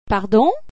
Pardon ?   uhvuh-ee